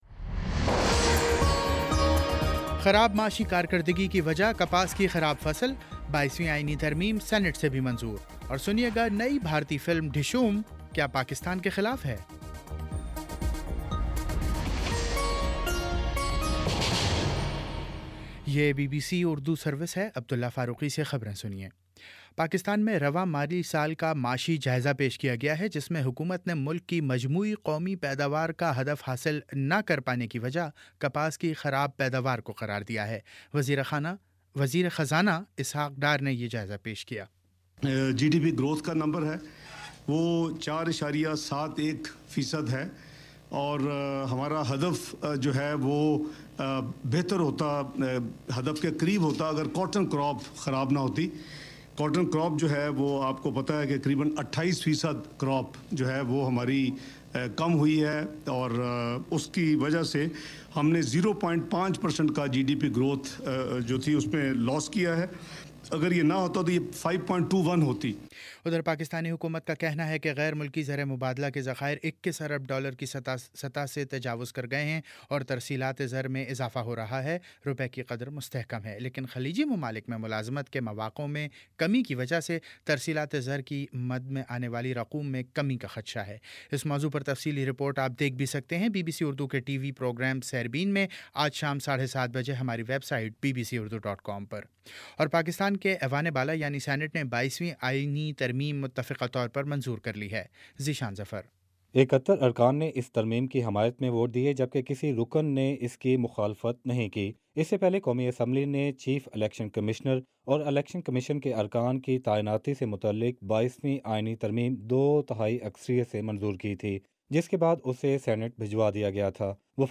جون 02: شام پانچ بجے کا نیوز بُلیٹن